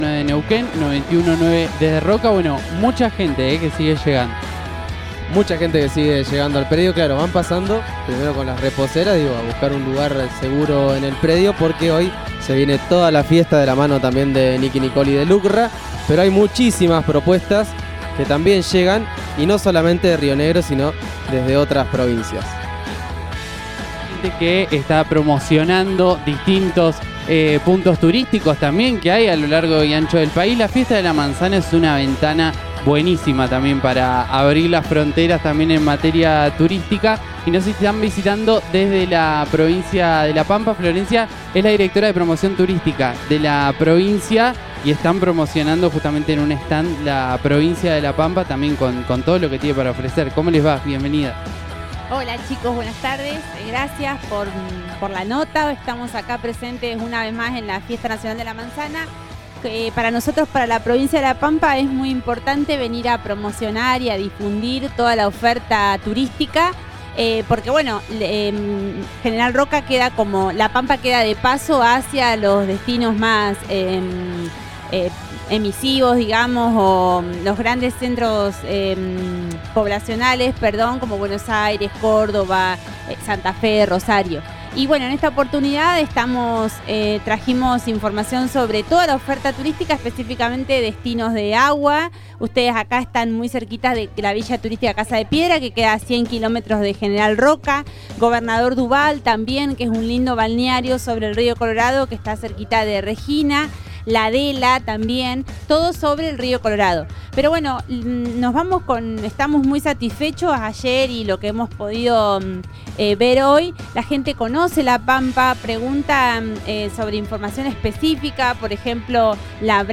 El stand de Turismo de la vecina provincia pasó por los micrófonos de RN Radio para compartir las opciones disponibles de cara a los próximos meses.